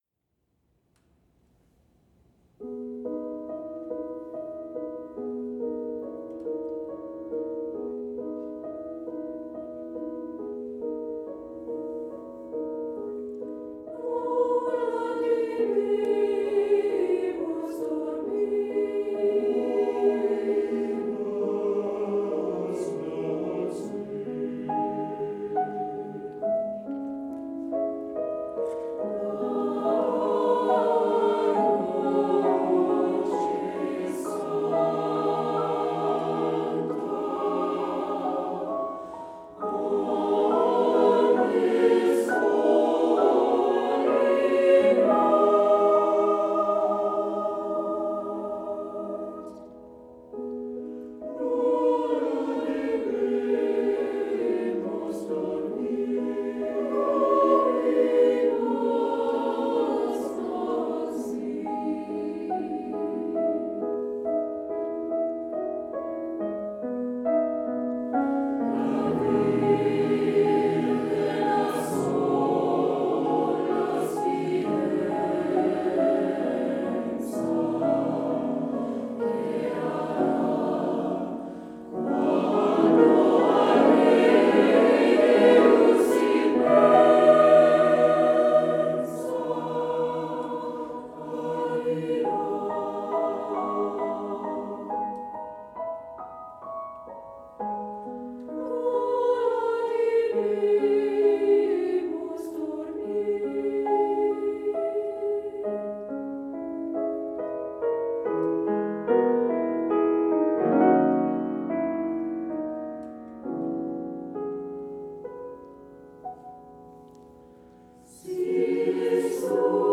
Ci-dessous, je vous mets (pendant quelques jours) un morceau chanté par ma chorale la semaine précédente.